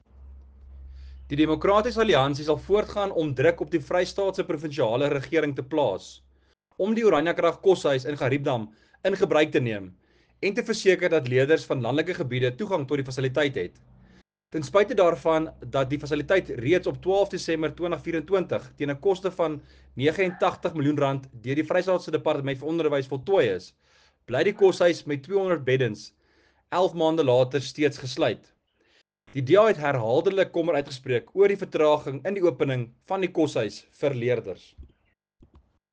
Afrikaans soundbites by Werner Pretorius MPL and